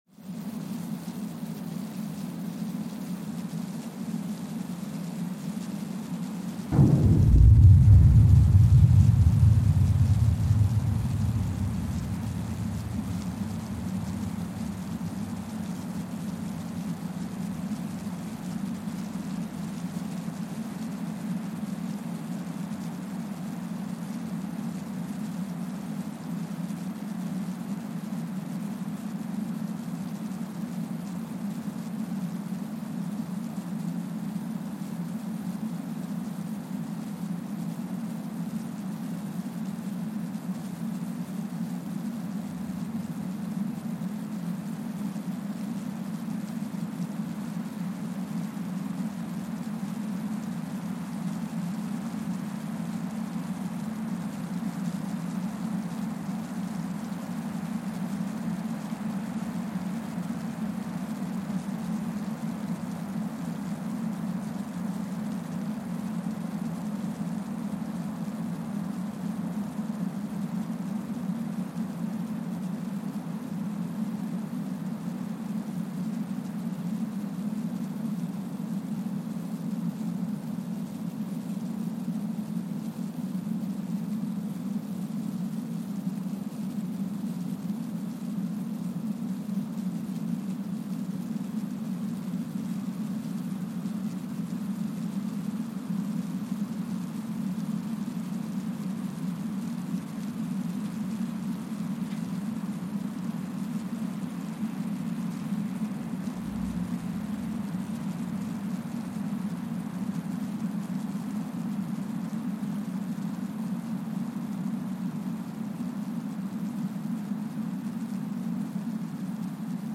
Kwajalein Atoll, Marshall Islands (seismic) archived on May 23, 2023
Sensor : Streckeisen STS-5A Seismometer
Speedup : ×1,000 (transposed up about 10 octaves)
Loop duration (audio) : 05:45 (stereo)